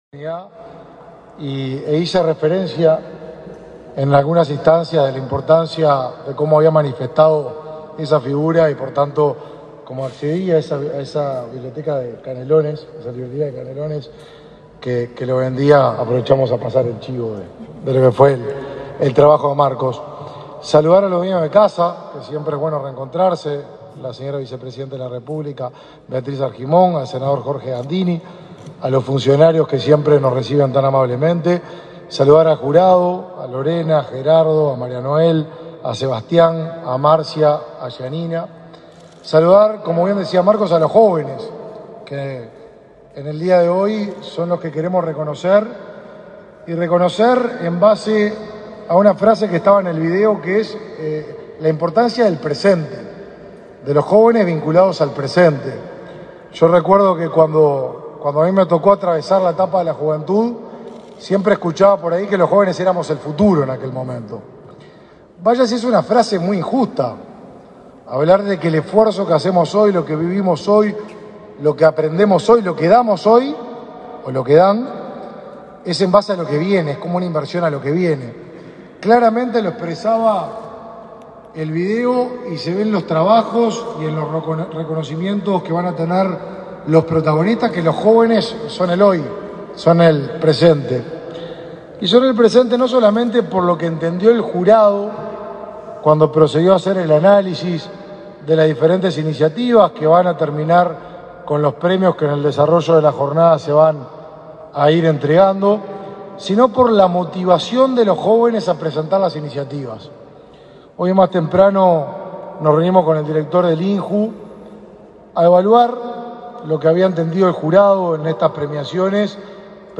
Conferencia de prensa por el Día Internacional de la Juventud
Conferencia de prensa por el Día Internacional de la Juventud 13/08/2022 Compartir Facebook X Copiar enlace WhatsApp LinkedIn Con la presencia de la vicepresidenta de la República, Beatríz Argimón, este 12 de agosto se celebró el Día Internacional de la Juventud y se entregaron distinciones a los seis ganadores de la entrega de los premios del Instituto Nacional de la Juventud (INJU). Participaron el ministro de Desarrollo Social, Martín Lema, y el director del INJU, Felipe Paullier.